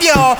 YOYOVOX   -L.wav